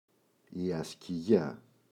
ασκιγιά, η [aski’ʝa]